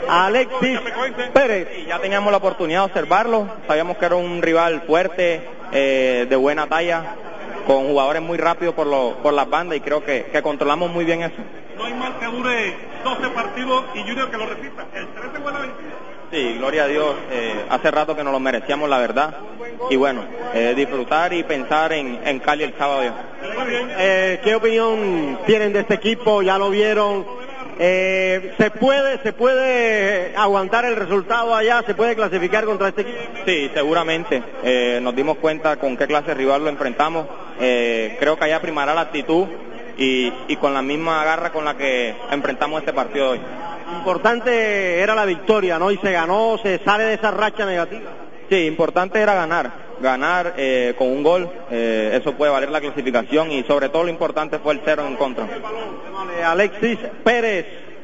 Escuche la reacción de los jugadores tiburones tras su regreso a la victoria.